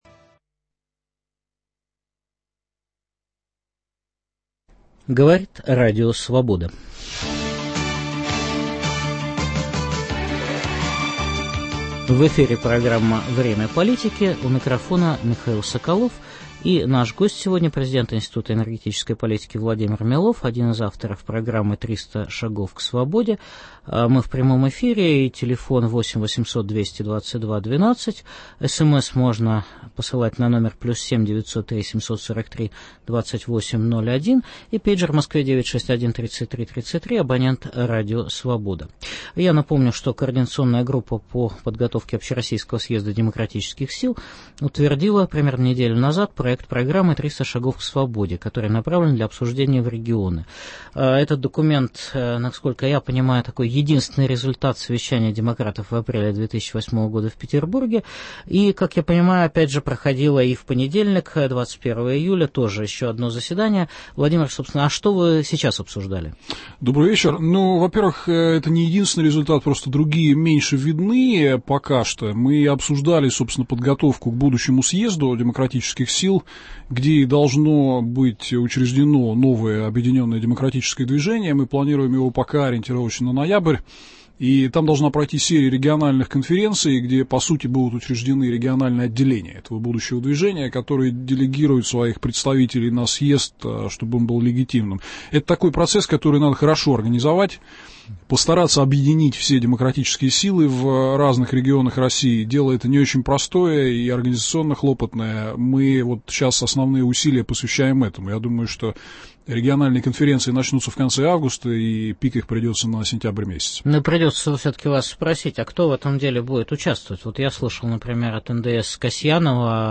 В прямом эфире обсуждается программа демократических сил «300 шагов к свободе». Гость в студии – соавтор программы, президент Институт энергетической политики Владимир Милов.